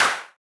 TS - CLAP (3).wav